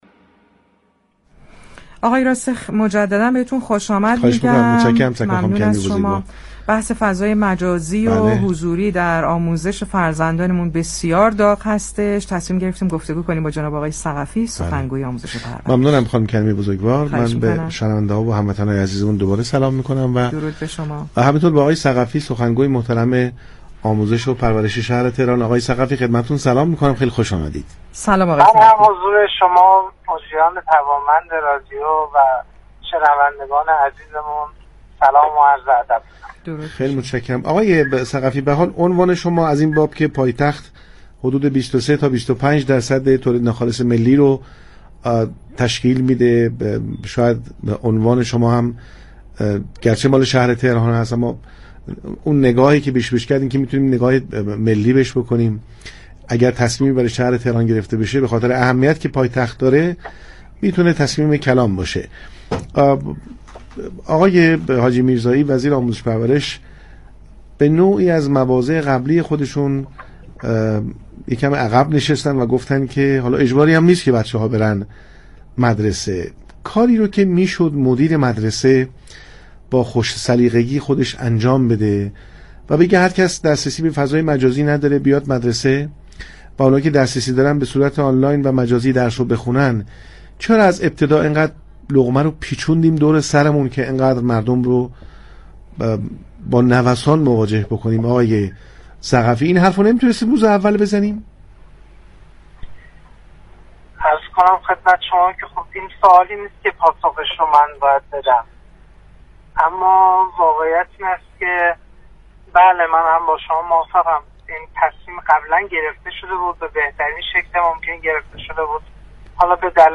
با برنامه پارك شهر گفت و گو كرد.